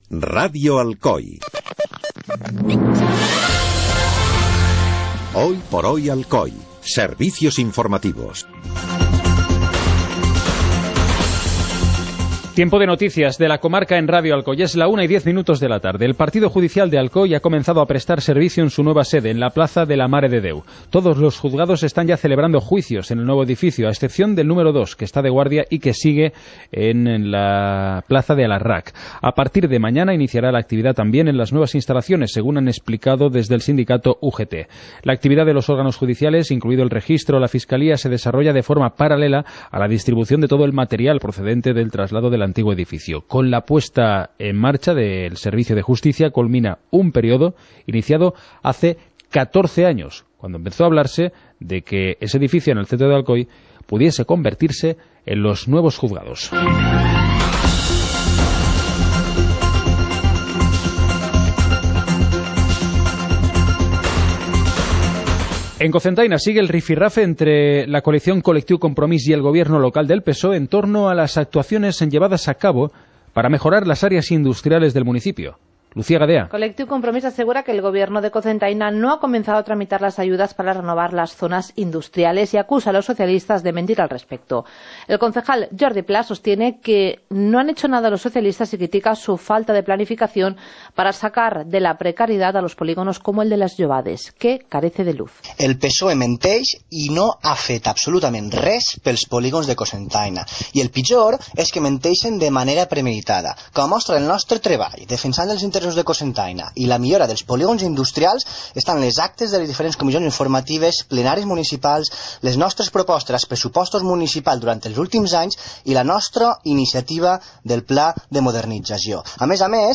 Informativo comarcal - martes, 07 de marzo de 2017